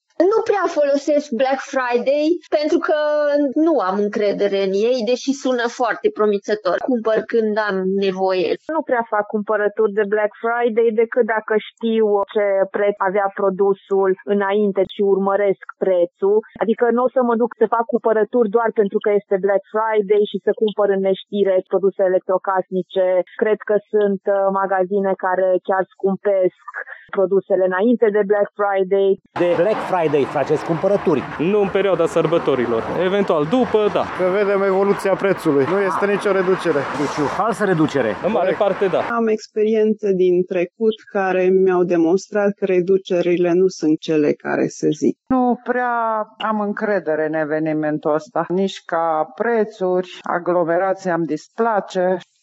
Târgumureșenii nu prea au încredere în reducerile anunțate de comercianți de Black Friday: